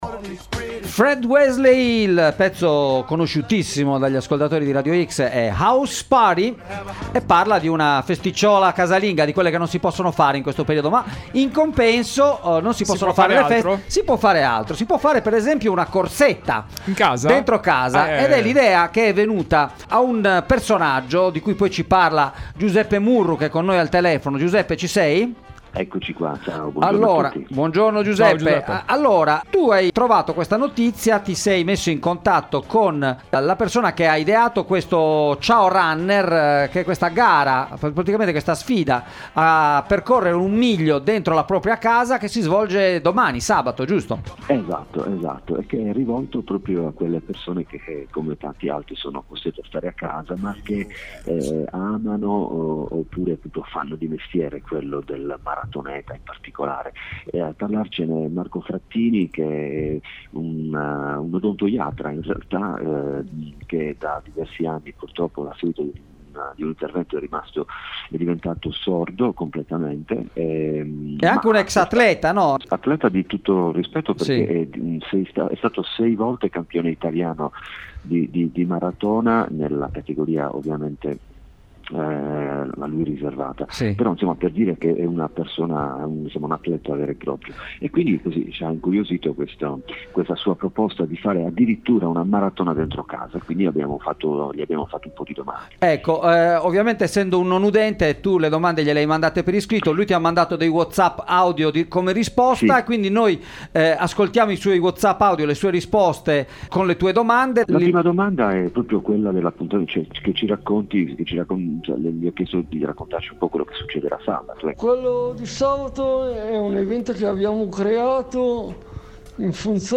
Una corsa “smart” ai tempi del Covid-19 – intervista